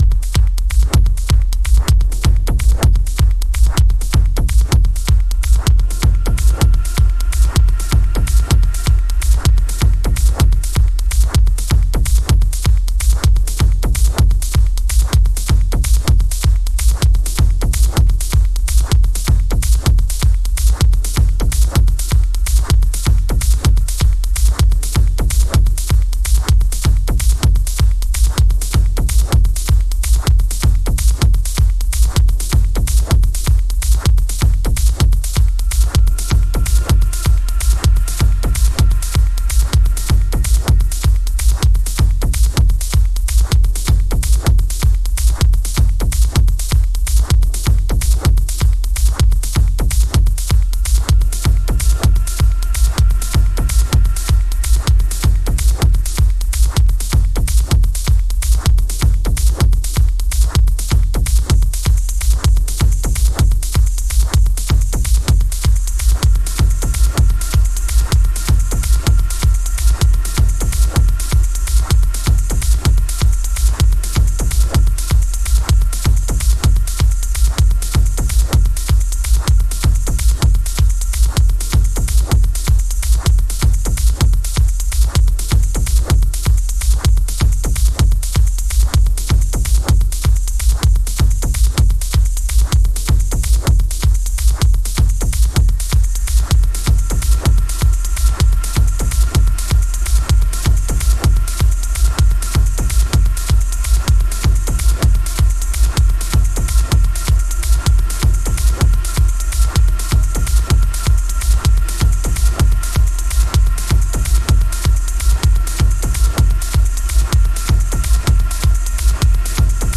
House / Techno
低域でうねるソフトなトランス感とアブストラクトな音響がマッチしたミニマルテクノ。